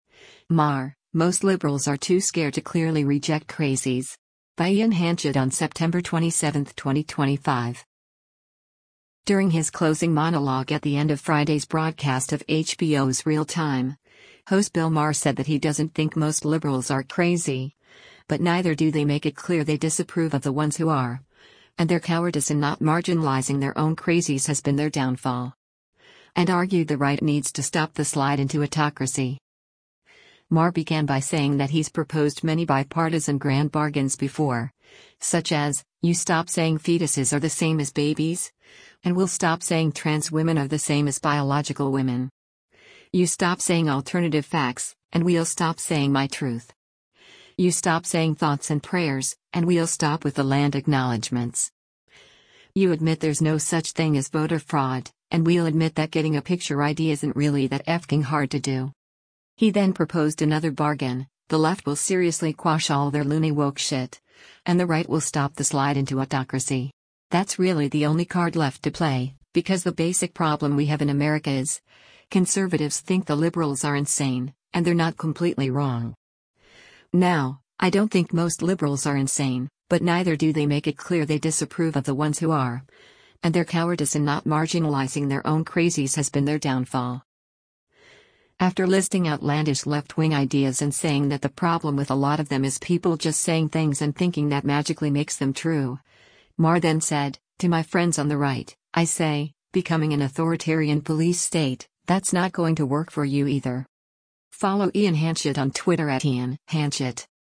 During his closing monologue at the end of Friday’s broadcast of HBO’s “Real Time,” host Bill Maher said that he doesn’t think most liberals are crazy, “but neither do they make it clear they disapprove of the ones who are, and their cowardice in not marginalizing their own crazies has been their downfall.”